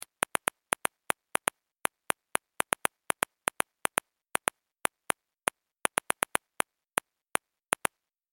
دانلود آهنگ کیبورد 12 از افکت صوتی اشیاء
جلوه های صوتی
دانلود صدای کیبورد 12 از ساعد نیوز با لینک مستقیم و کیفیت بالا